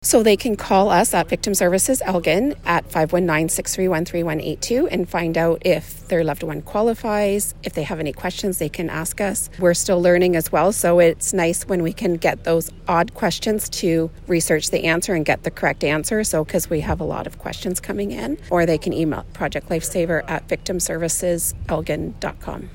She explains how families or caregivers interested in enrolling a loved one in Project Lifesaver, can learn more.